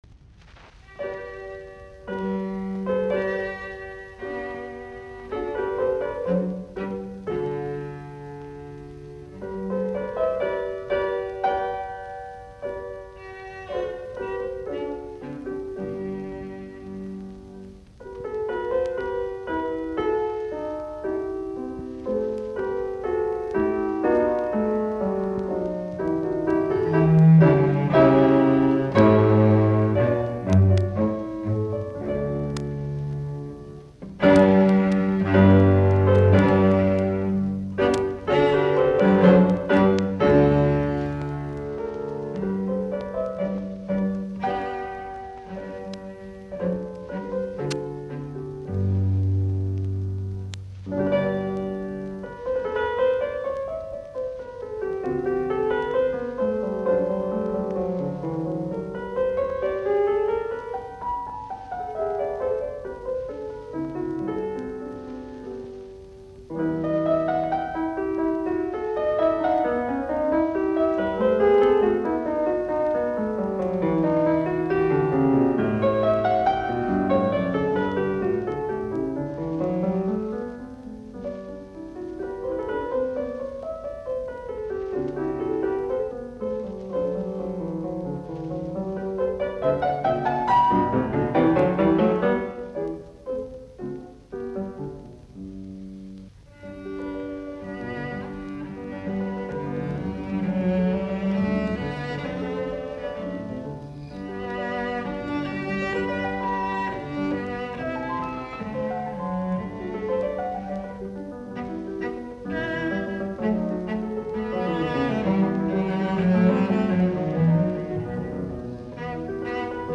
Twelve variations in G major on a theme from Handel's "Judas Maccabaeus" for Violoncello and Piano